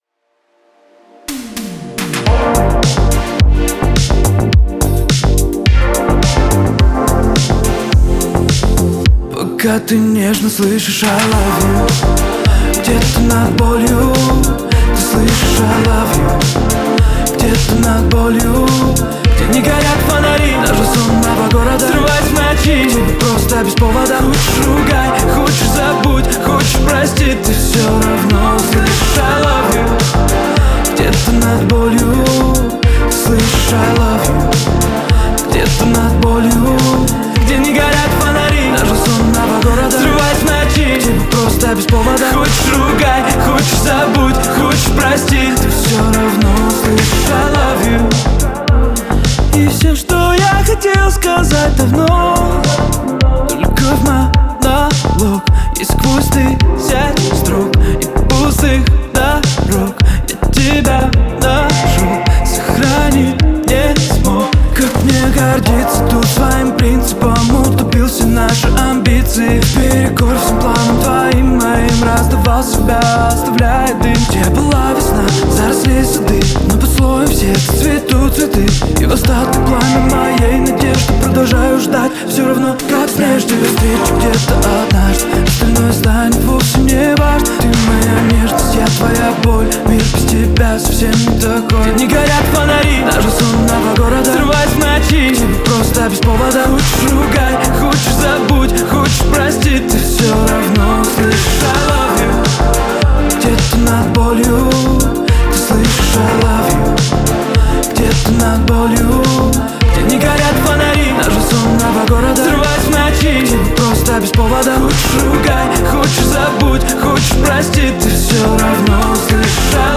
Нежная, но динамичная песня о любви и верности.